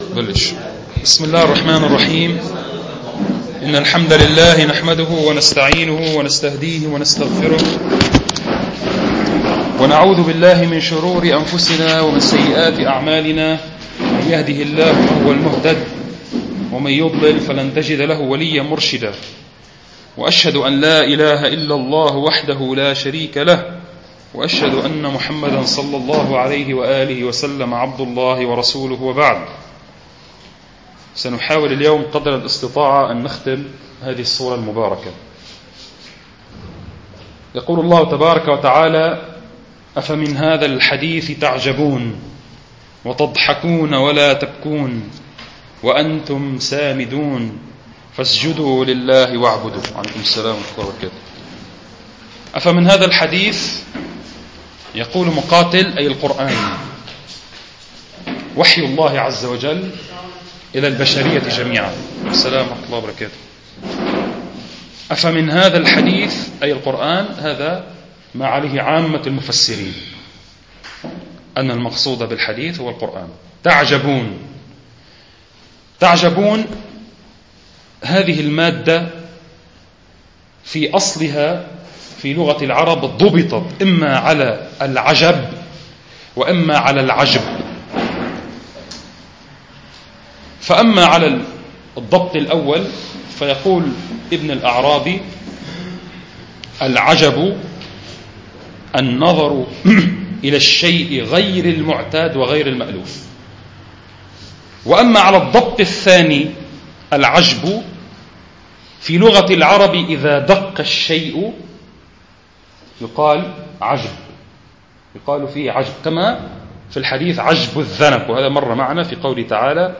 المكان : مدرسة المنار الإسلامية